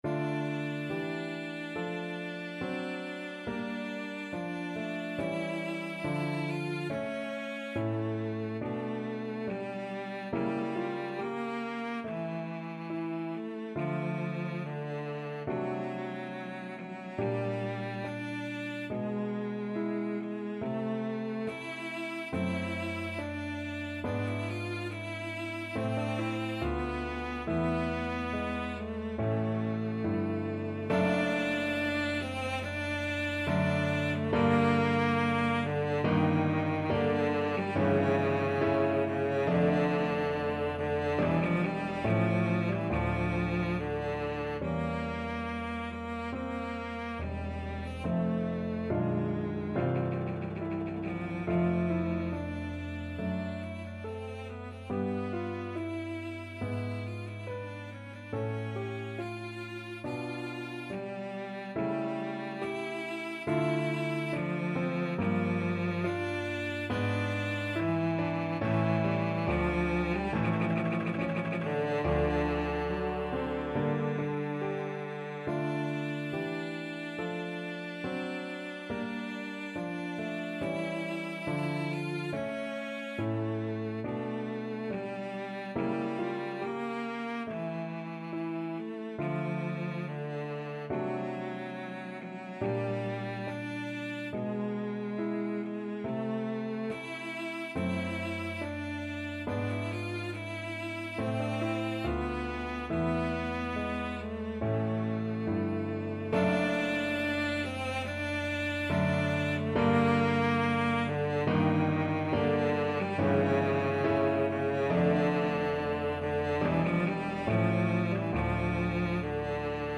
Classical Purcell, Henry Trio Sonata in G minor, Z.780 Cello version
Play (or use space bar on your keyboard) Pause Music Playalong - Piano Accompaniment Playalong Band Accompaniment not yet available reset tempo print settings full screen
Cello
Adagio =70
D minor (Sounding Pitch) (View more D minor Music for Cello )
4/4 (View more 4/4 Music)
Classical (View more Classical Cello Music)